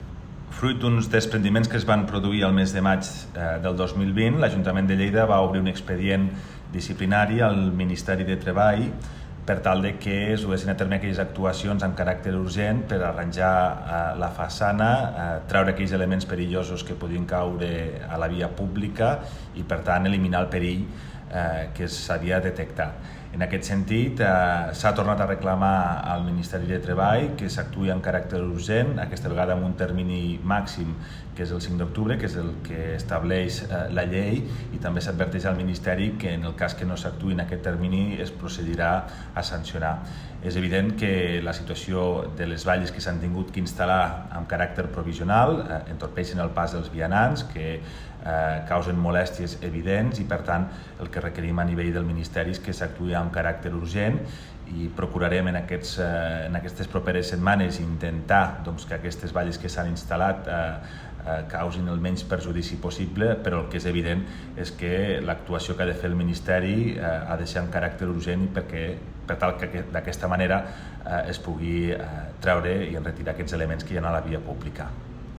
tall-de-veu-del-primer-tinent-dalcalde-toni-postius-sobre-lactuacio-a-ledifici-dels-sindicats